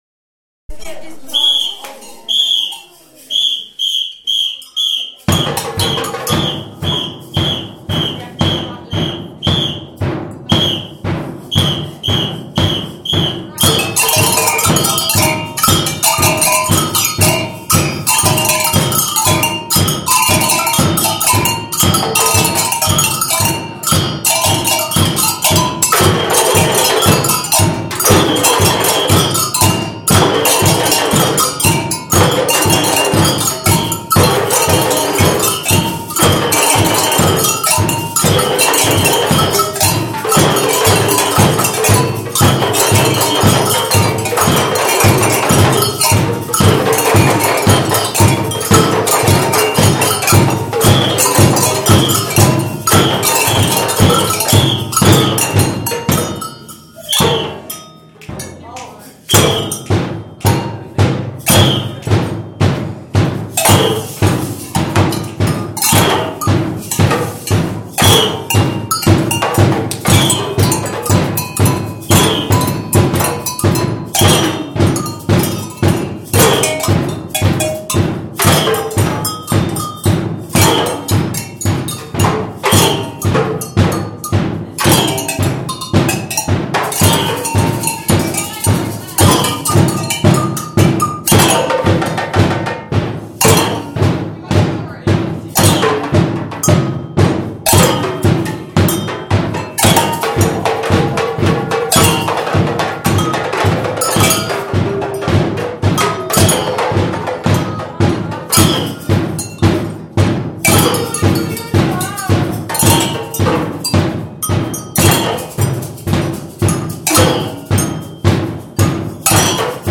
8TO Samba Band Jube 2014